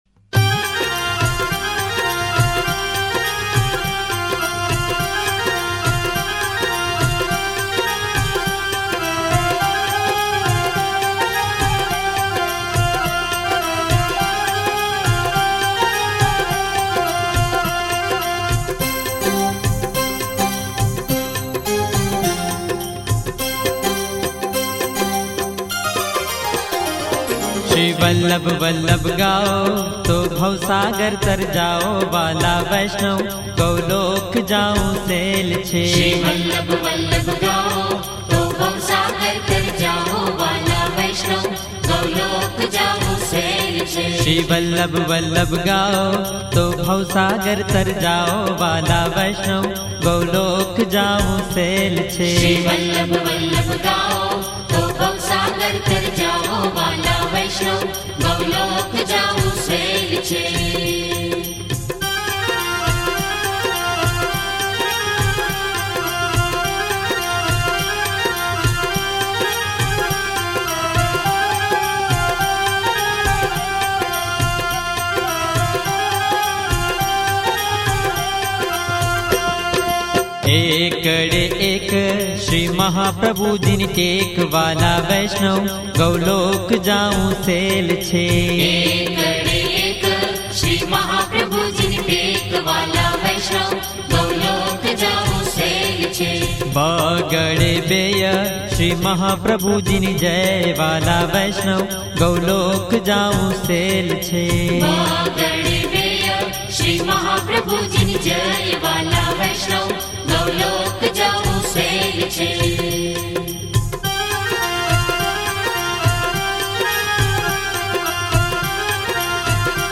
Gujarati Bhajan